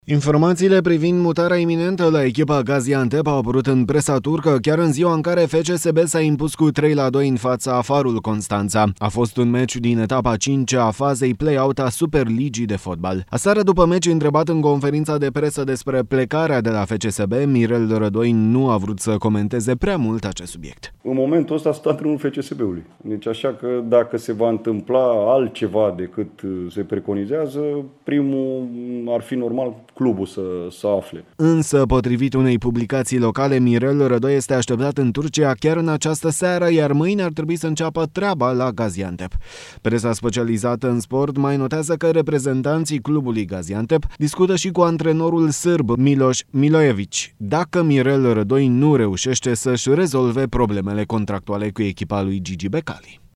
Aseară, după meci, întrebat în conferința de presă despre plecarea de la FCSB, Mirel Rădoi nu a vrut să comenteze prea mult acest subiect: